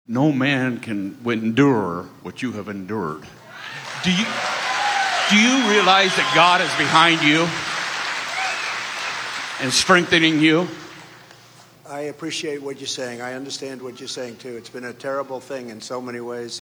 Trump made his comments after a man in the crowd on the Jackson County Fairgrounds asked this question.